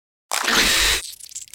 Звук паука открывающего рот и рычащего